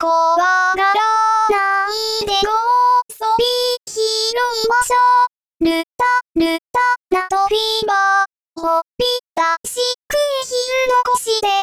一番最初に音取りしたキーがすべて-1だった
E-miner(ホ短調)
１度違うだけなのに比べるとやっぱりズレていますね